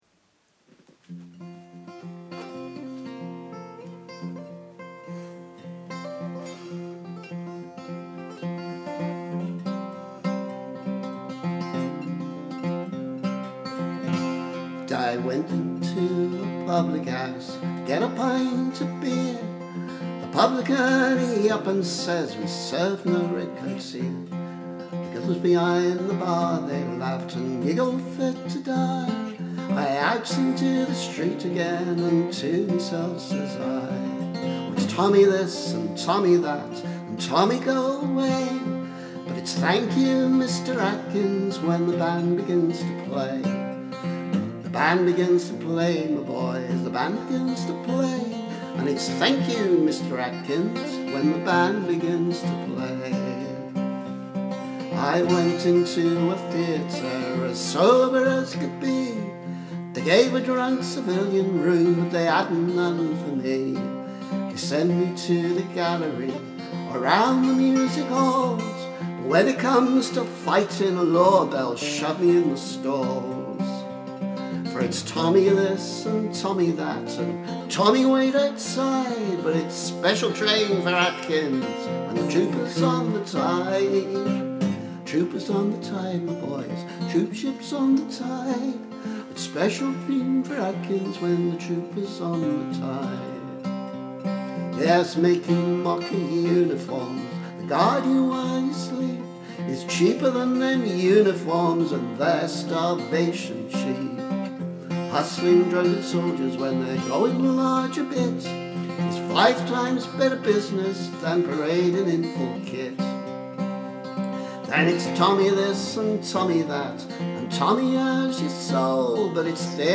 Actually a very rough demo, as I was in ‘make-it-up-as-you-go-along’ mode.